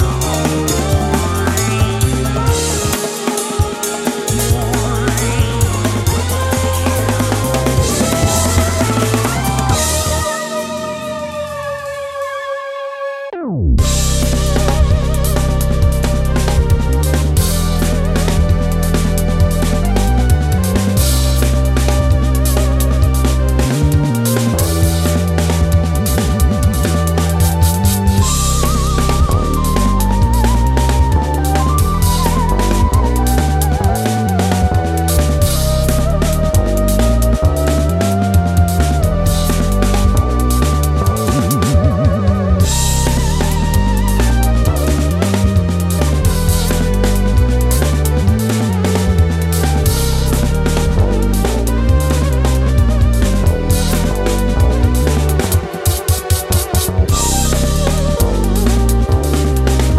drum & bass, jazz, funk